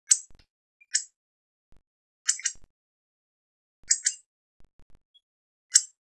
Predilige i boschi di latifoglie, in particolare le querce, ma si trova anche in zone ai margini dei boschi con campi coltivati e siepi alberate; quando è posato sulle cime delle piante il suo richiamo caratteristico è un
"cick" (286 KB) ben poco musicale.
frosone.wav